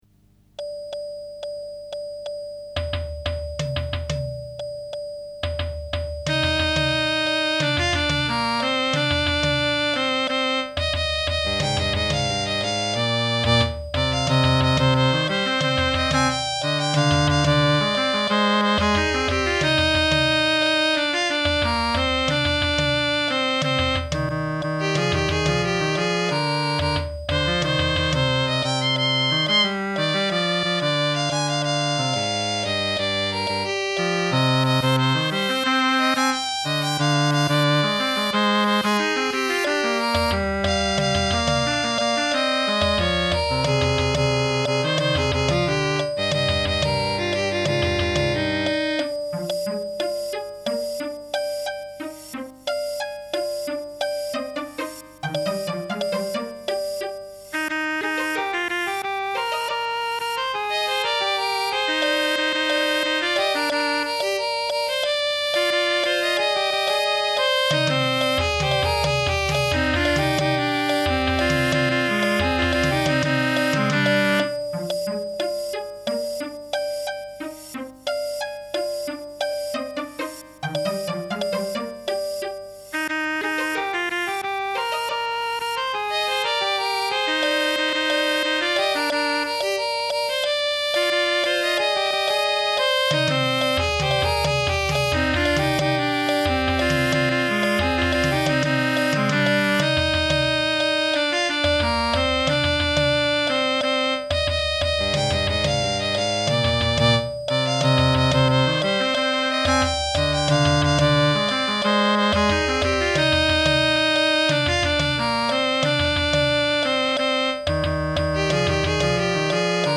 strings and percussion